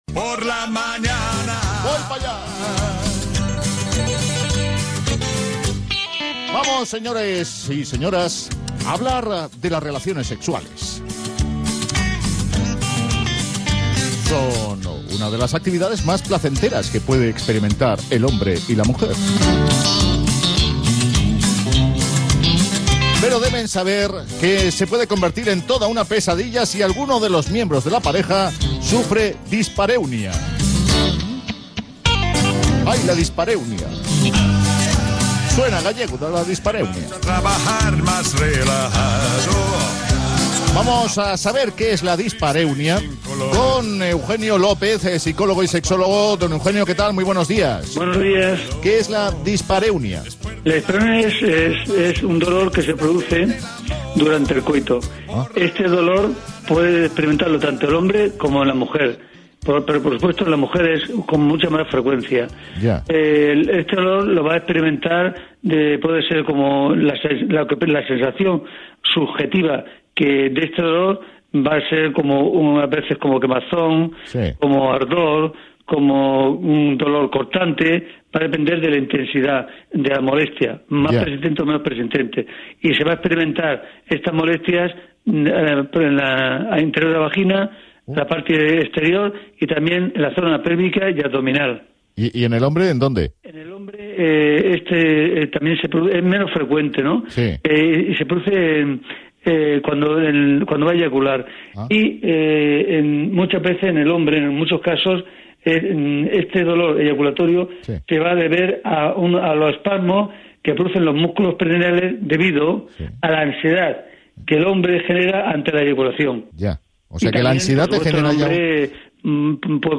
Entrevista en el programa de Punto Radio Barcelona «A día de hoy»